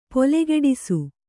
♪ polegeḍisu